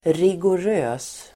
Uttal: [rigor'ö:s]